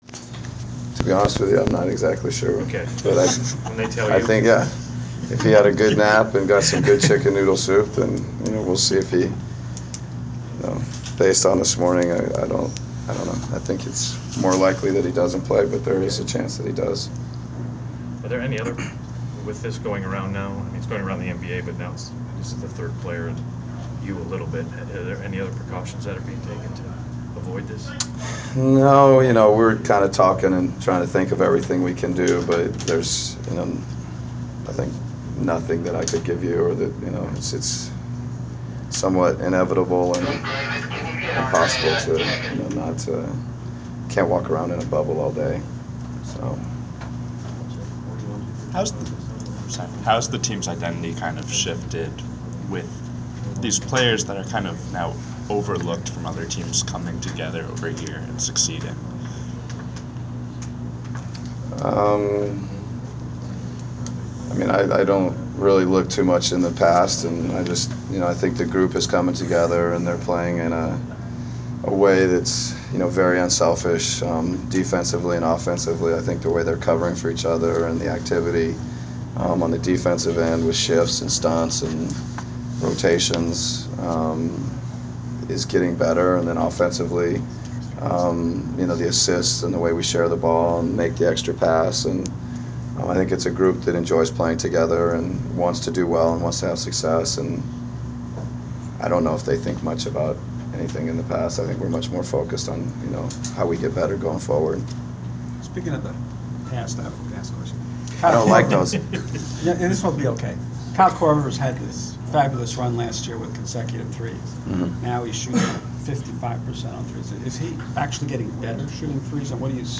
Inside the Inquirer: Pregame interview with Atlanta Hawks’ head coach Mike Budenholzer (12/15/14)
We attended the pregame press conference of Atlanta Hawks’ head coach Mike Budenholzer before his team’s home contest against the Chicago Bulls on Dec. 15. Topics included the overall health of the Hawks, contributions of Kyle Korver to the team and defending Derrick Rose.